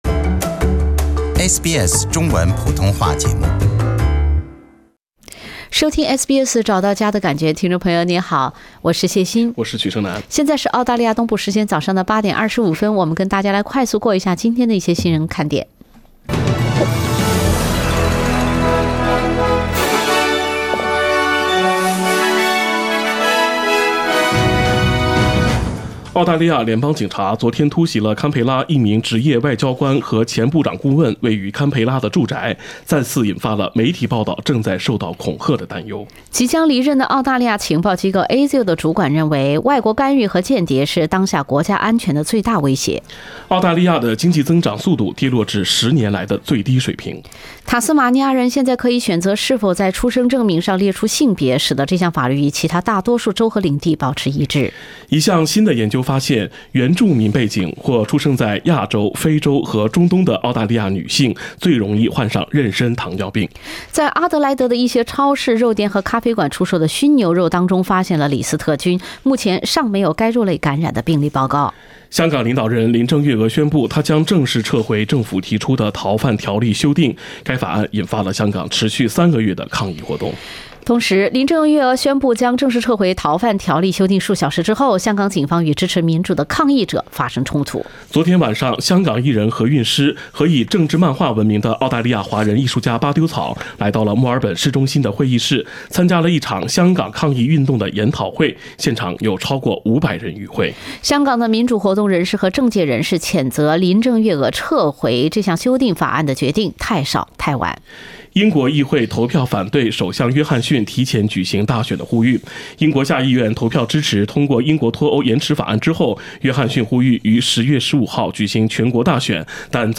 SBS早新闻 （9月5日）